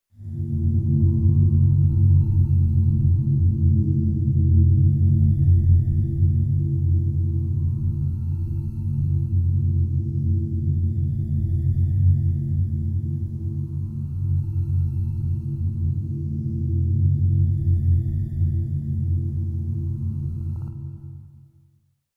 Efecto especial de voces en sueños 03